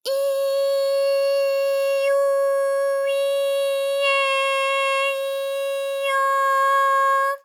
ALYS-DB-001-JPN - First Japanese UTAU vocal library of ALYS.
i_i_u_i_e_i_o.wav